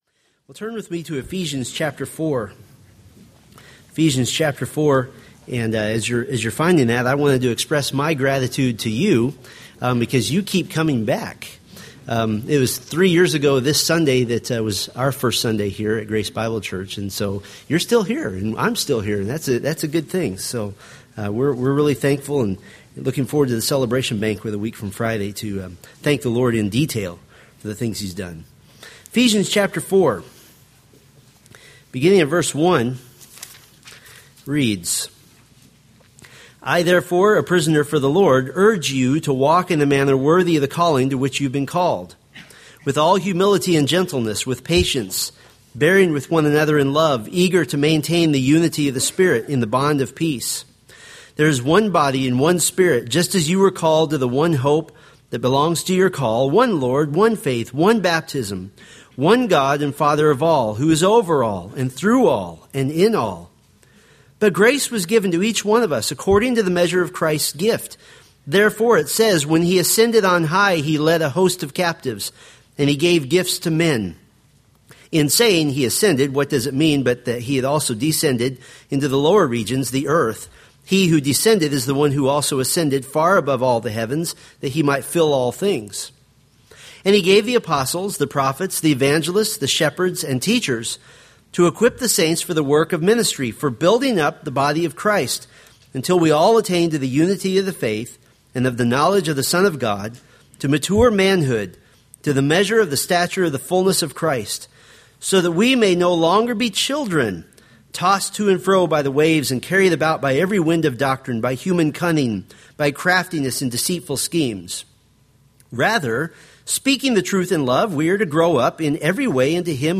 Ephesians Sermon Series